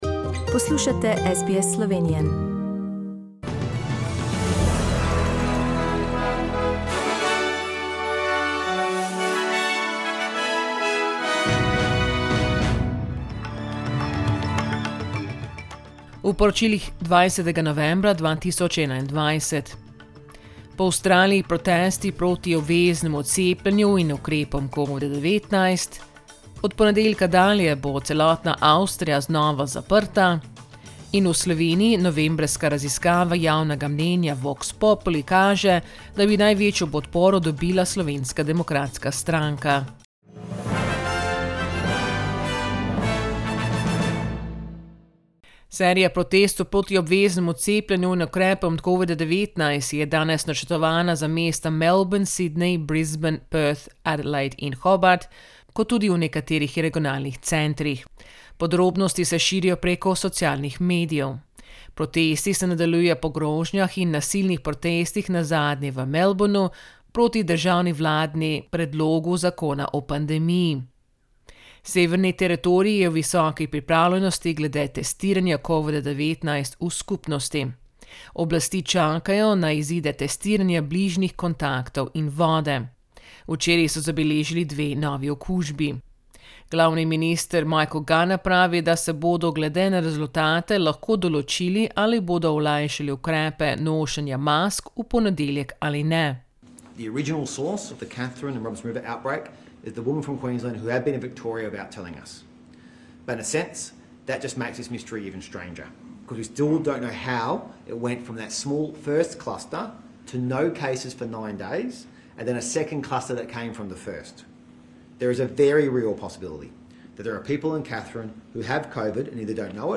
Poročila Radia SBS v slovenščini 20.novembra